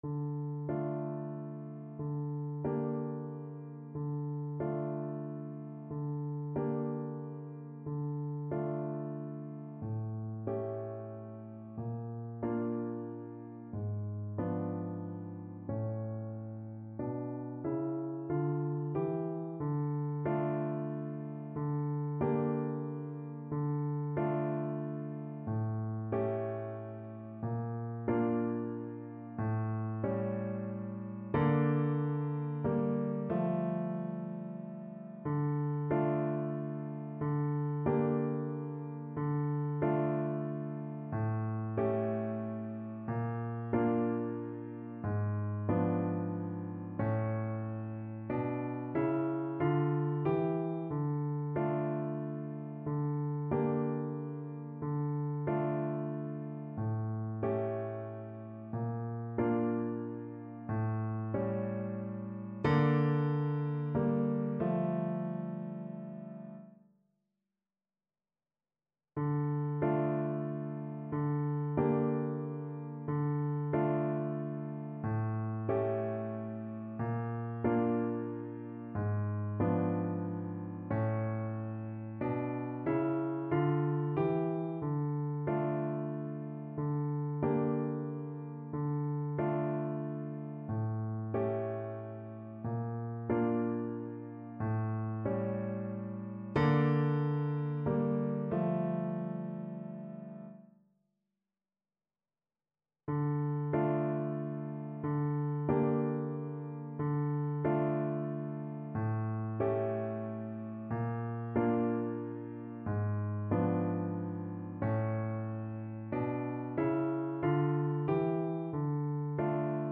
kolęda: Mizerna, cicha (na klarnet i fortepian)
Symulacja akompaniamentu